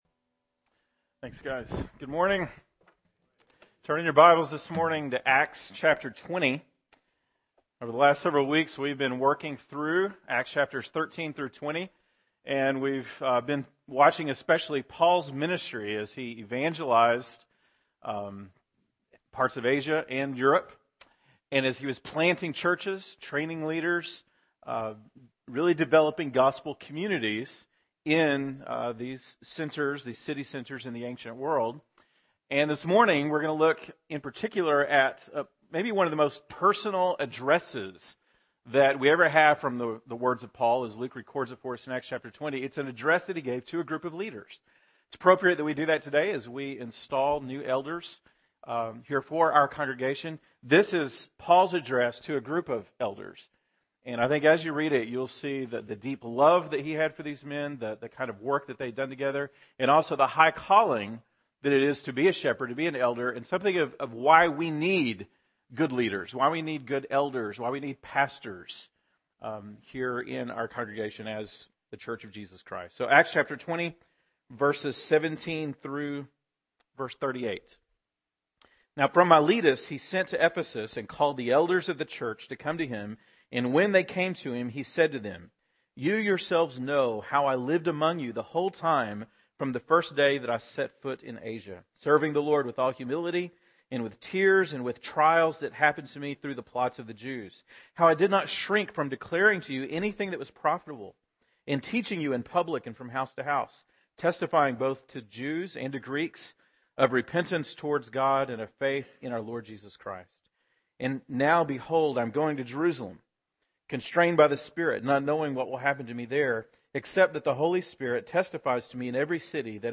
September 22, 2013 (Sunday Morning)